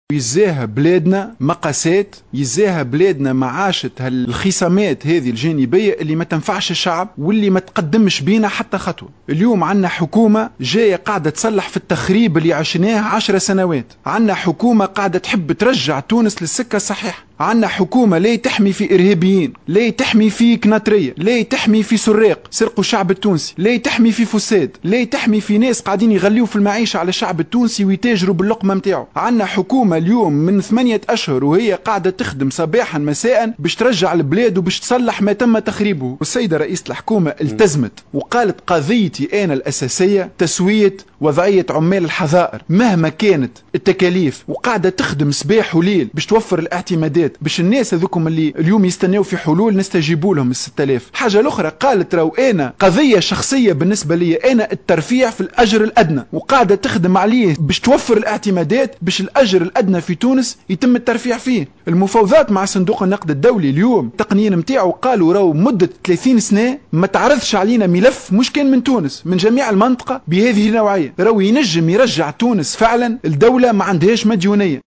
وبين النصيبي خلال حوار له مع الاذاعة الوطنية، ان رئيسة الحكومة التزمت بتسوية وضعية عمال الحضائر وتوفير الاعتمادات لذلك، بالإضافة الى الترفيع في الاجر الأدنى.